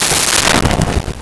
Door Metal Slam 02
Door Metal Slam 02.wav